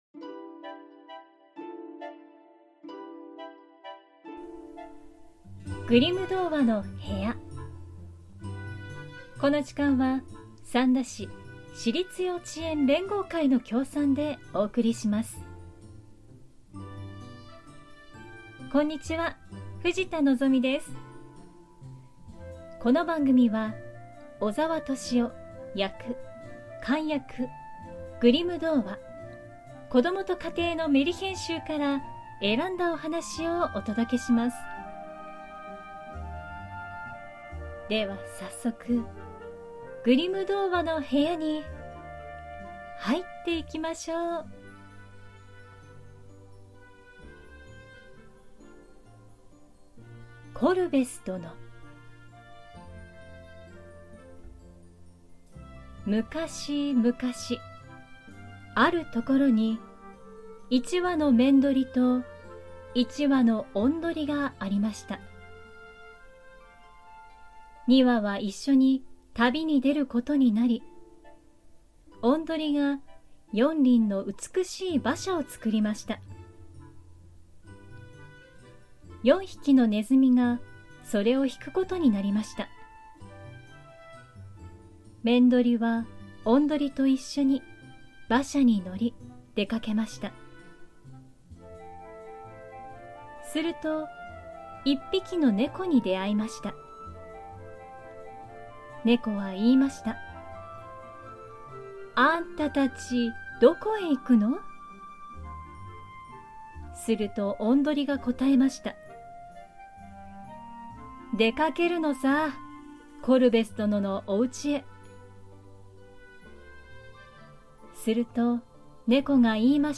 グリム兄弟によって集められたメルヒェン（昔話）を、翻訳そのままに読み聞かせします📖